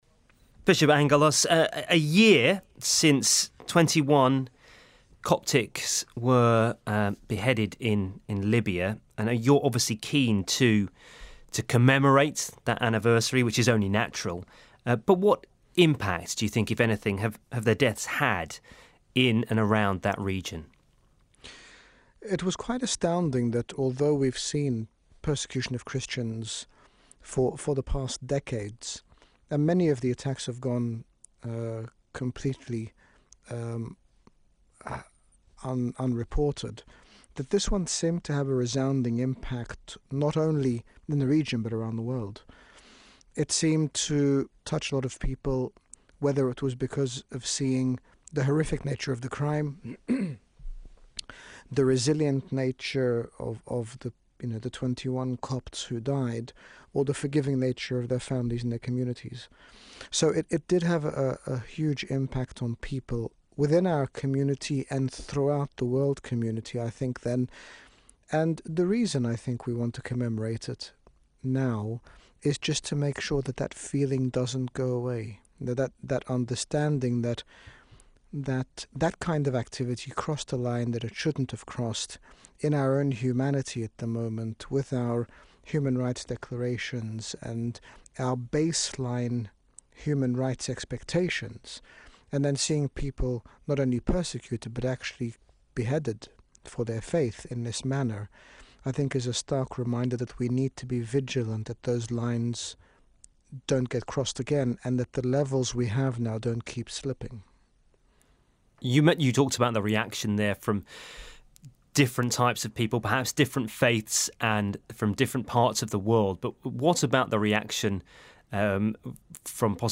His Grace Bishop Angaelos, General Bishop of the Coptic Orthodox Church in the United Kingdom, speaks to the BBC about the impact that the murder of 21 Coptic Christians in Libya (Feb 2013) had on the world community, while also commenting on the current refugee crisis.
BBC Radio Libya Anniversary.mp3